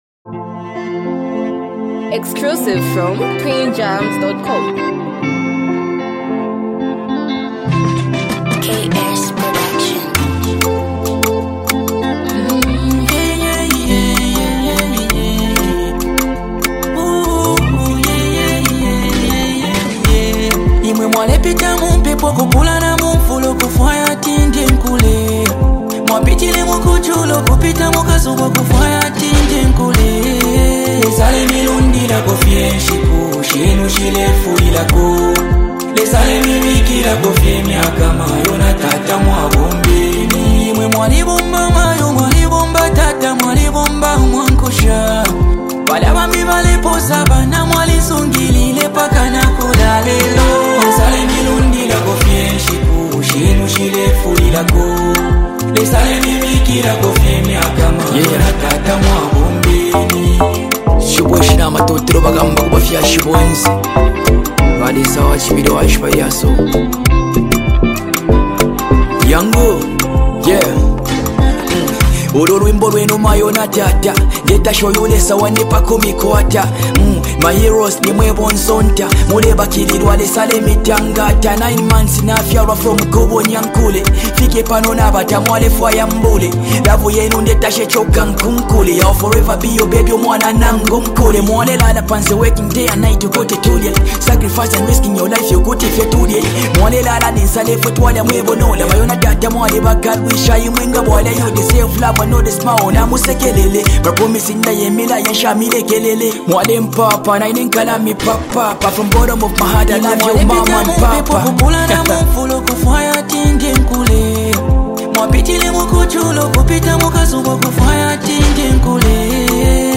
hype, street vibes, and a powerful hook with unique rap flow